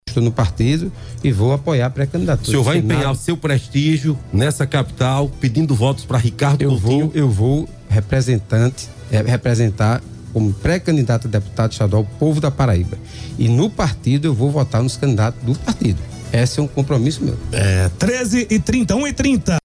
O ex-prefeito de João Pessoa Luciano Cartaxo (PT) desconversou sobre se empenhar na campanha do ex-governador Ricardo Coutinho (PT) ao Senado, se ele tiver condições jurídicas. Questionado sobre o assunto no Correio Debate, da 98 FM, o petista afirmou que representará o PT na condição de candidato a deputado estadual e apoiará os candidatos do partido, sem assegurar, de maneira clara, seu apoio a Coutinho.